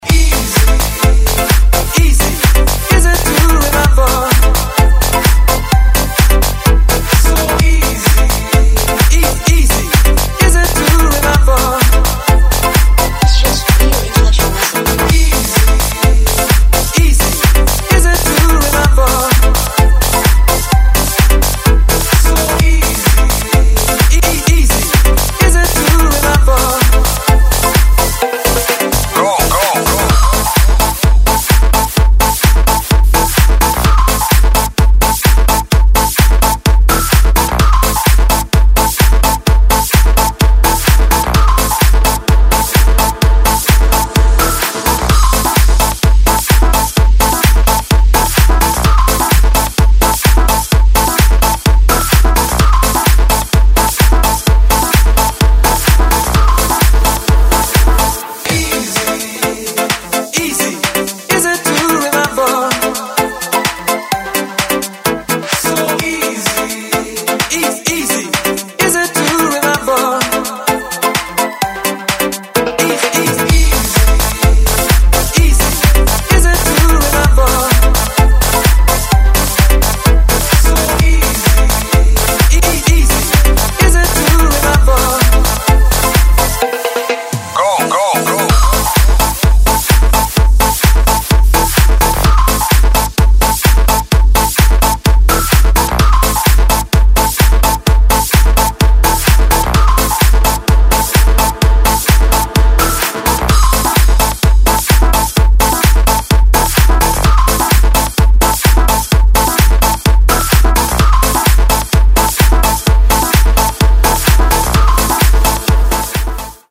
• Качество: 128, Stereo
Классный ремикс